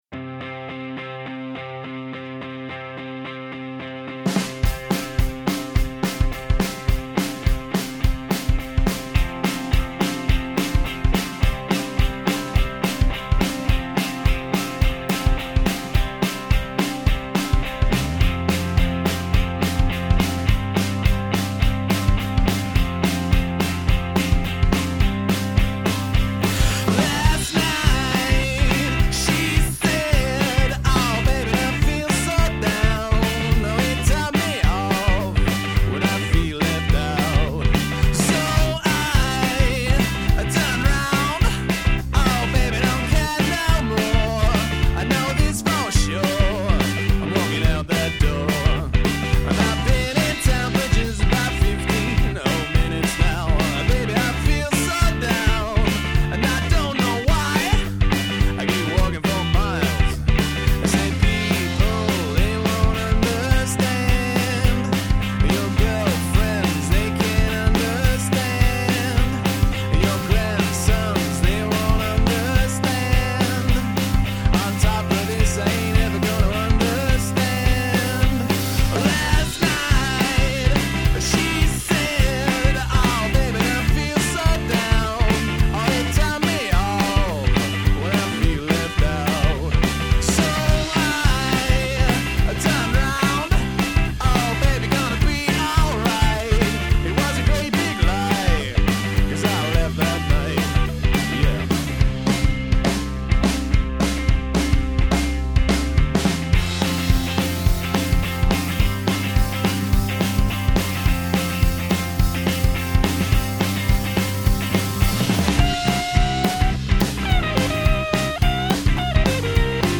• 3-piece